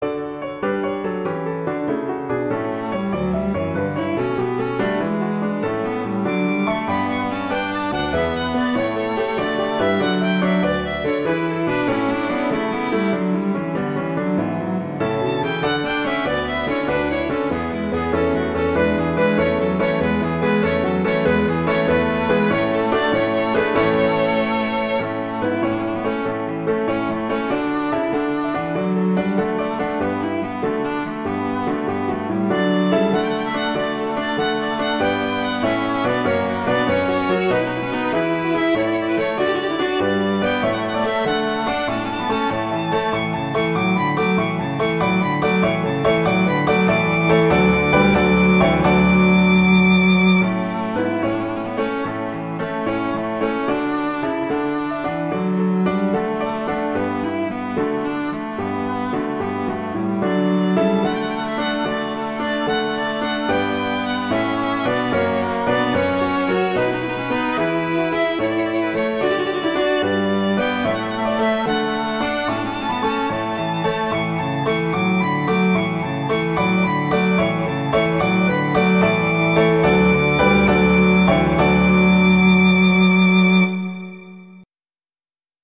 classical, wedding, traditional, easter, festival, love
D major
♩=46-144 BPM